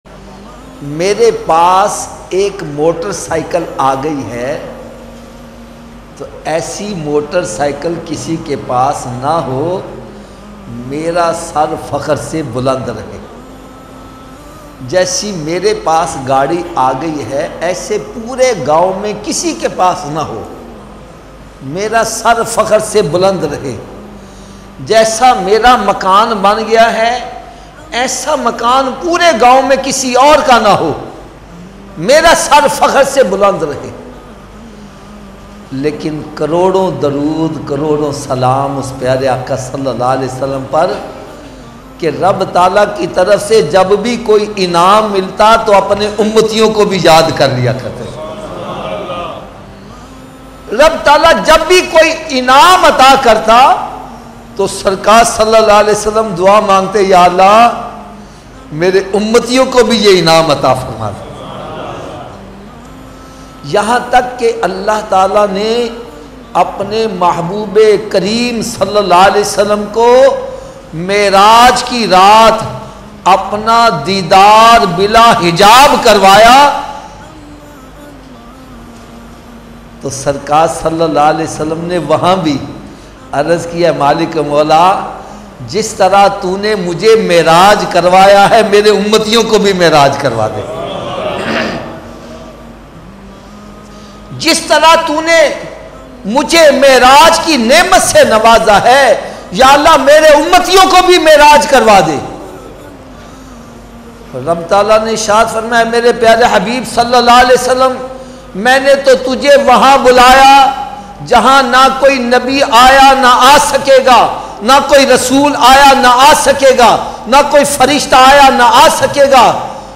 Namaz Kya Hai Latest Bayan 2020